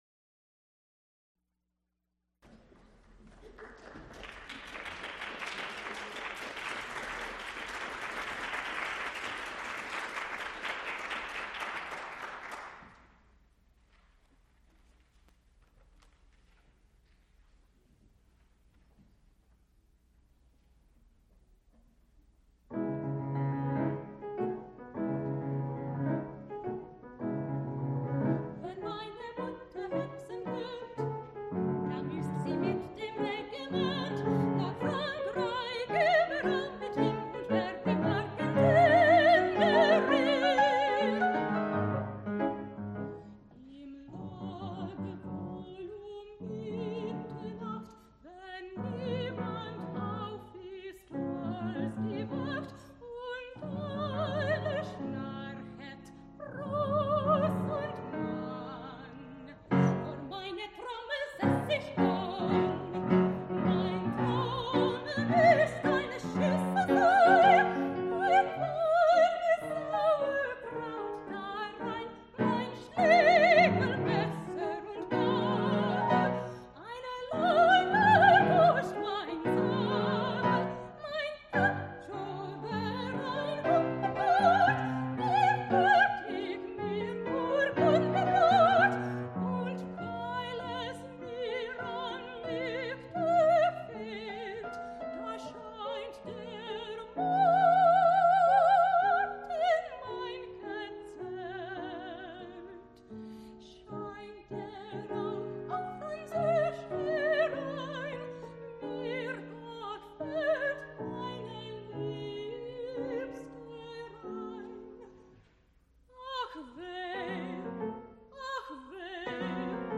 Recorded live February 13, 1980, Frick Fine Arts Auditorium, University of Pittsburgh.
musical performances
Songs (High voice) with piano Song cycles